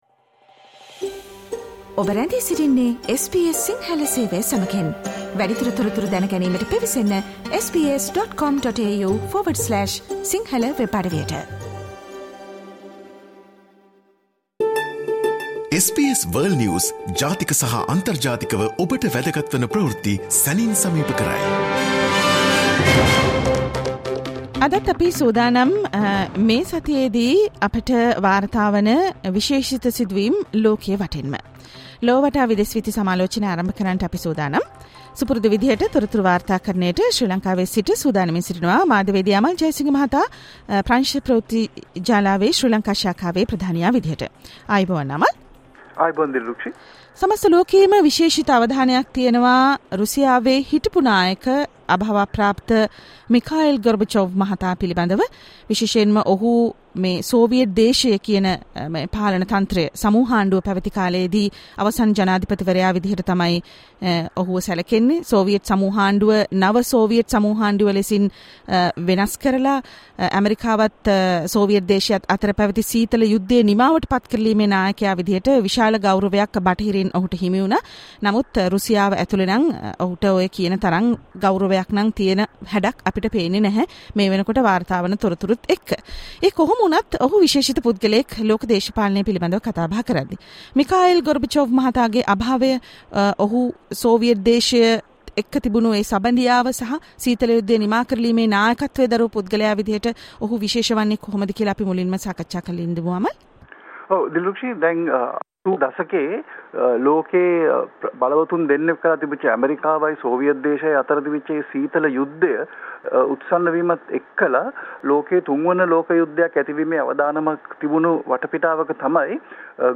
Putin Skips Gorbachev's Funeral while Jacqueline is banned from leaving India over money fraud: World News